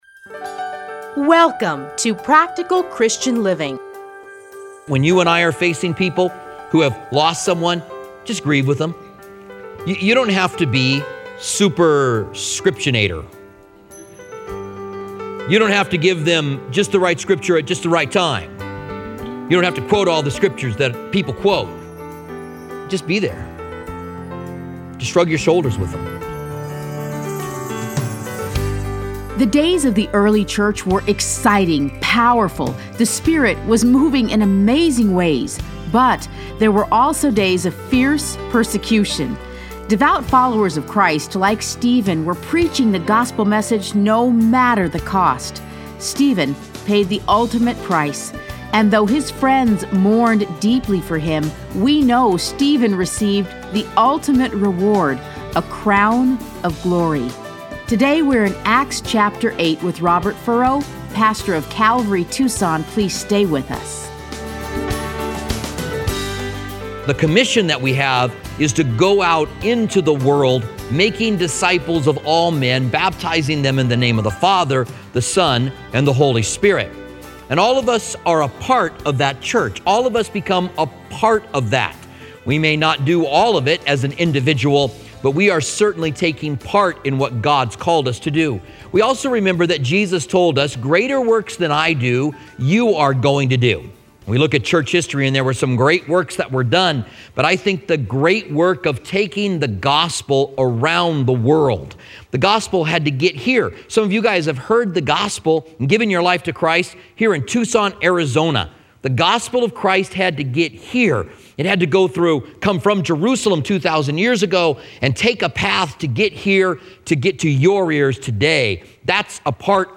Listen to a teaching from Acts 8.